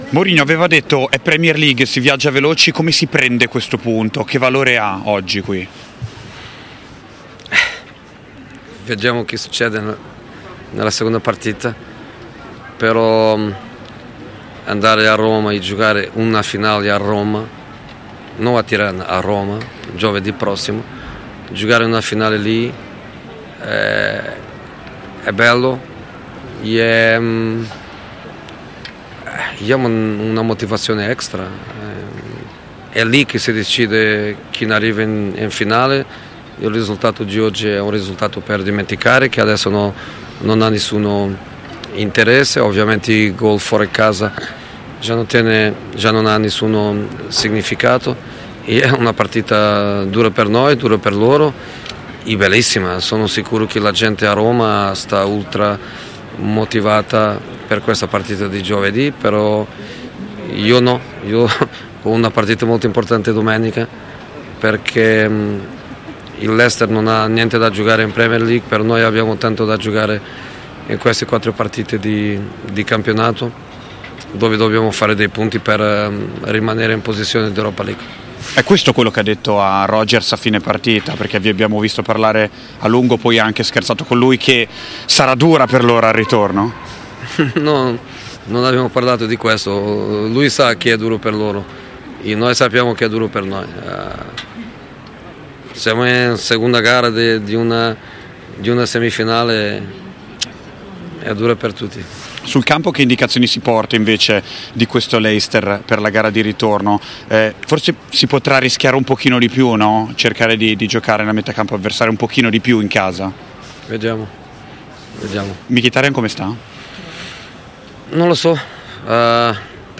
Al termine del match in Inghilterra contro il Leicester per l’andata della semifinale della Conference League, il Capitano della Roma, Lorenzo Pellegrini, ha rilasciato alcune dichiarazioni al riguardo: